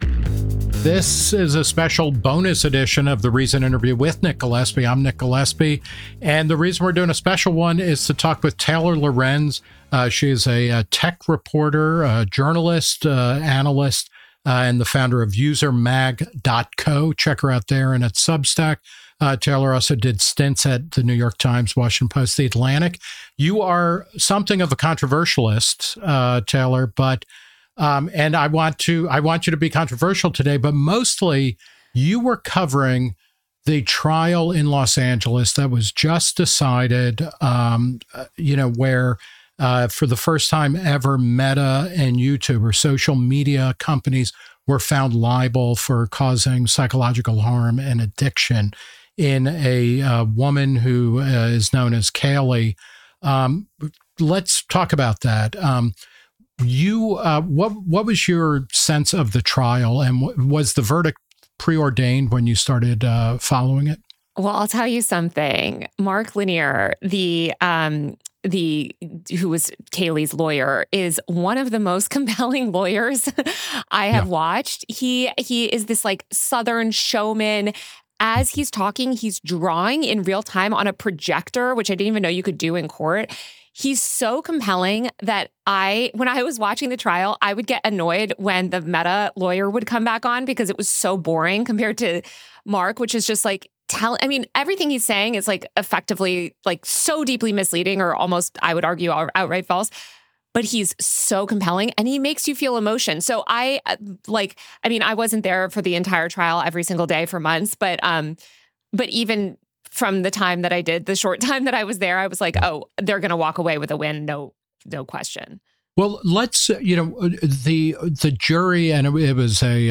Tech journalist Taylor Lorenz discusses the Meta trial, the moral panic around social media, and the risks of regulating online speech.
In this special bonus episode of The Reason Interview , Nick Gillespie talks with tech journalist Taylor Lorenz , founder of User Mag , who covered the Los Angeles trial.